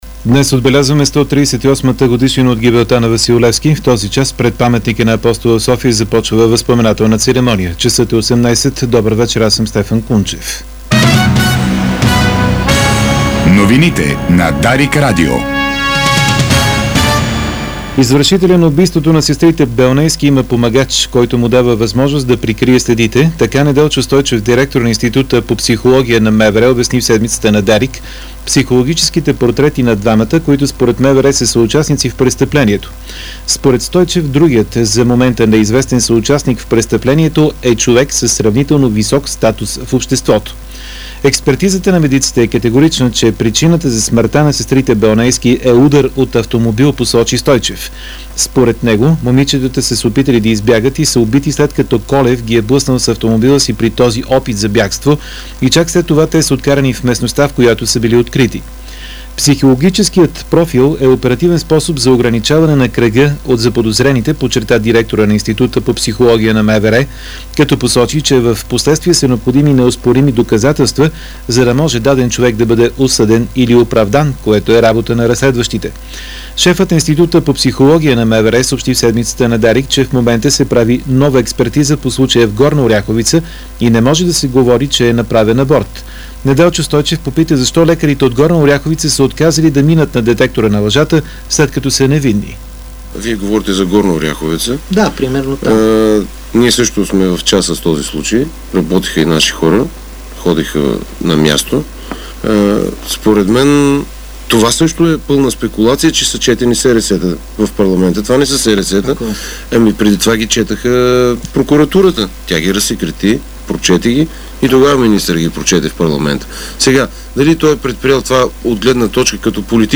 Обзорна информационна емисия - 19.02.2011